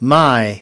14.My /maɪ/ :của tôi,của mình